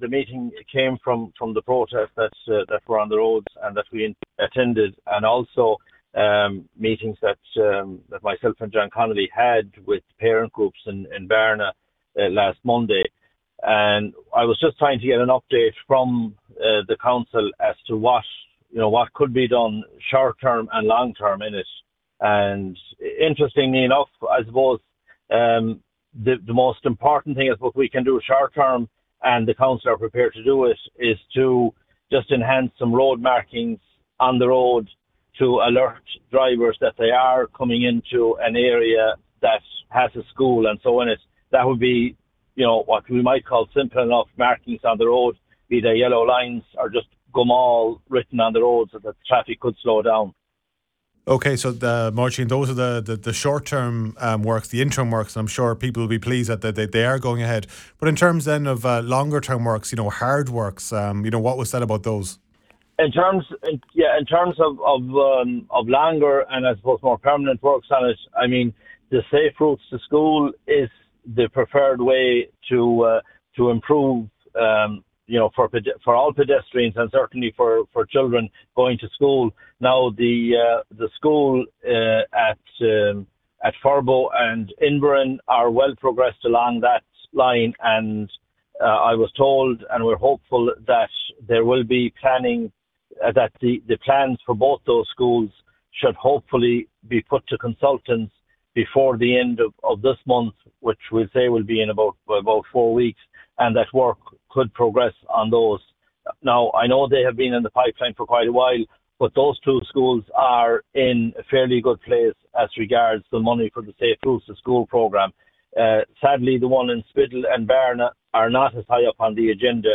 Councillor Mairtín Lee has since met with council officials, and says they’re willing to carry out short term measures like new and improved road markings.